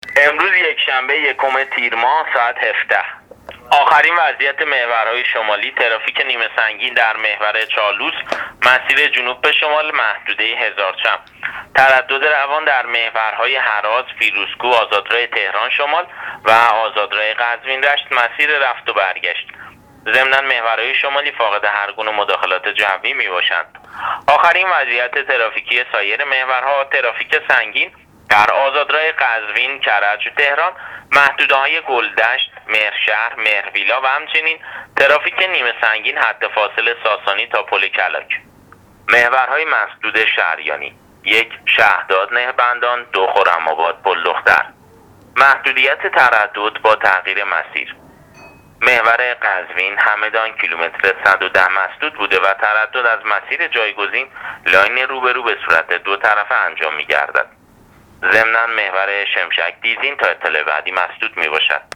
گزارش رادیو اینترنتی از وضعیت ترافیکی جاده‌ها تا ساعت ۱۷ اول تیر ۹۹